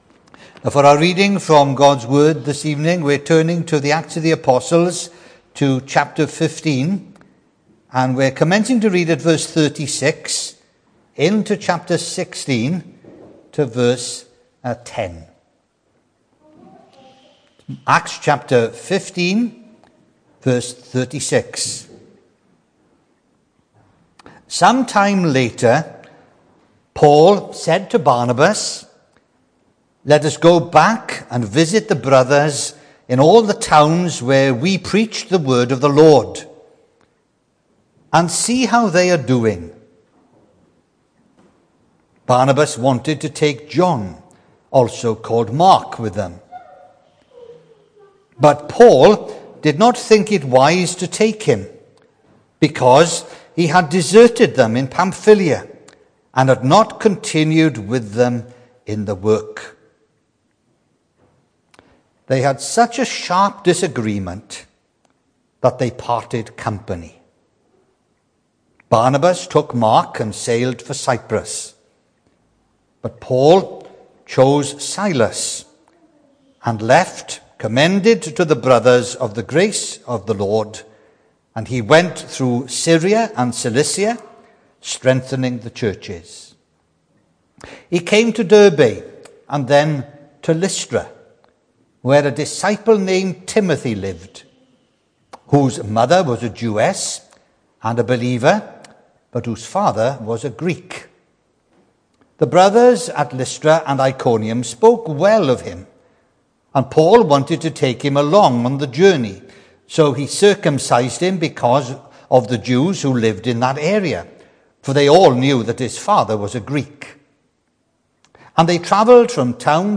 The 28th of August saw us hold our evening service from the building, with a livestream available via Facebook.
Sermon